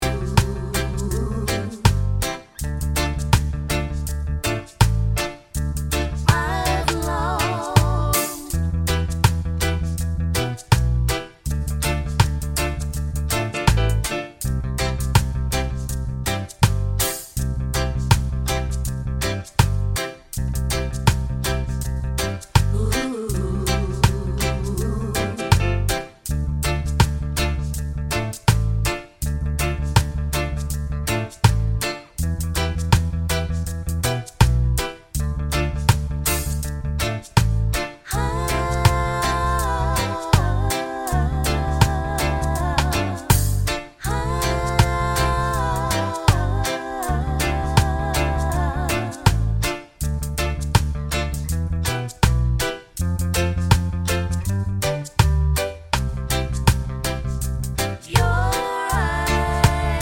no Backing Vocals Reggae 3:57 Buy £1.50